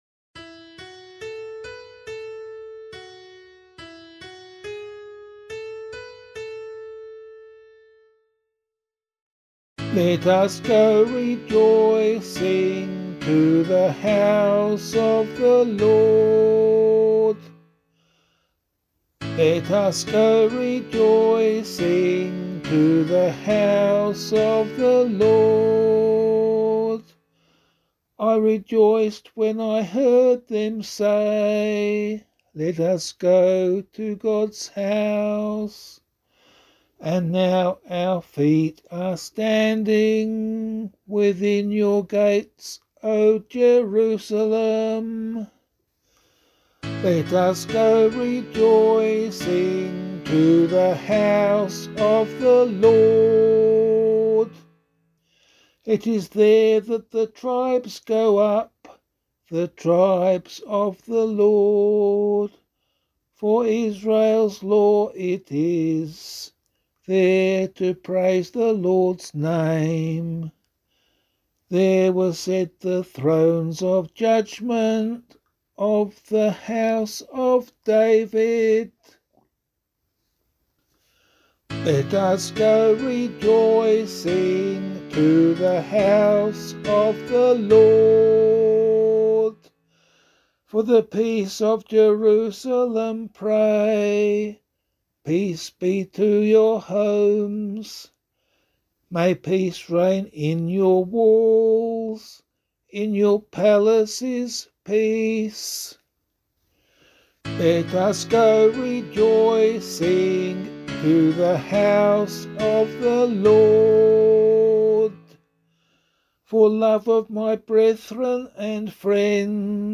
001 Advent 1 Psalm A [LiturgyShare 8 - Oz] - vocal.mp3